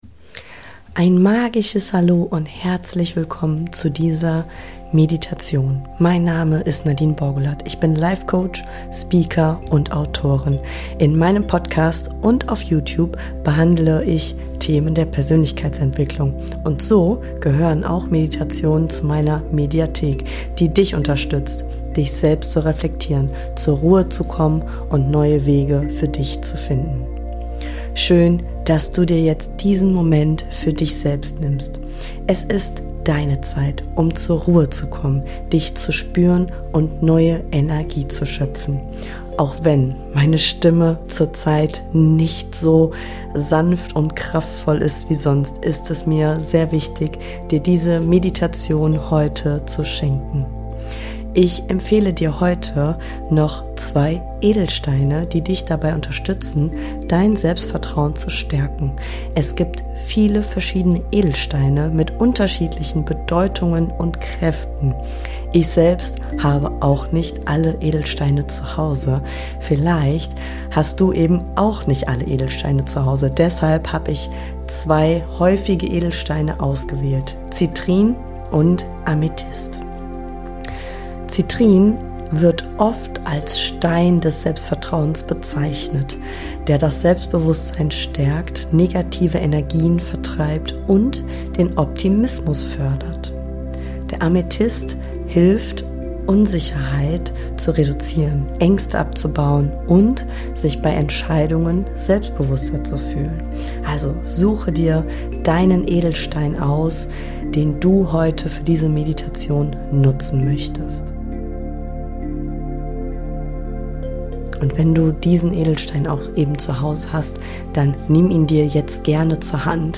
Geführte Meditation | Mut für deine Träume | Bali Inspiration ~ Boost your Mind to bright your Life Podcast